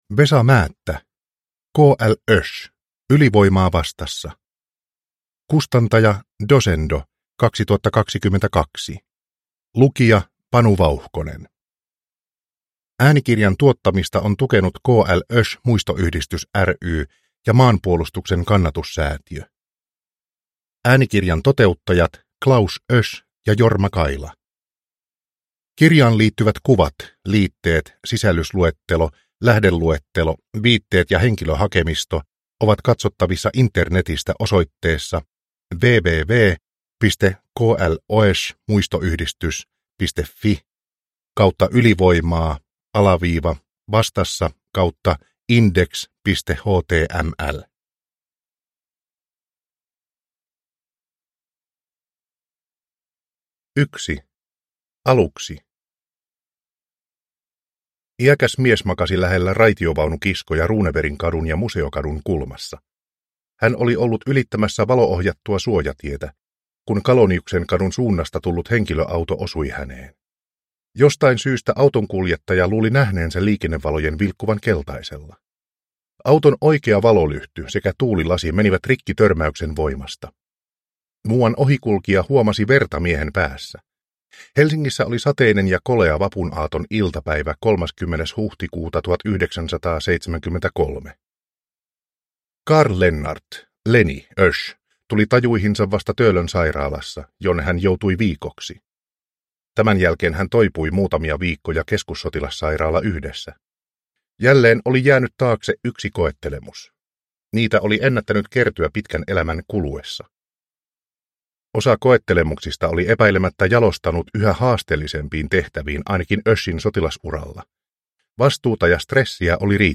K.L. Oesch – Ljudbok